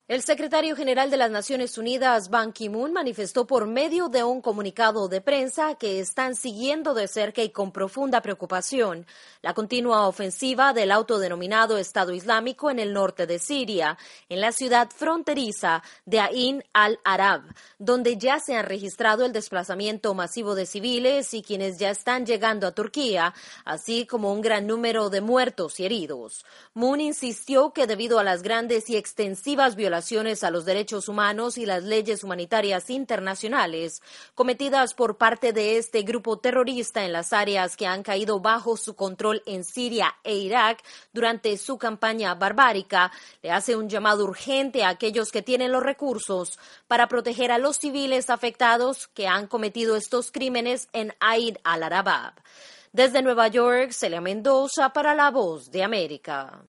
La ONU manifiesta preocupación frente a la escalada violenta adelantada por el grupo yihadista estado islámico, en el área fronteriza entre Siria y Turquía. Desde Nueva York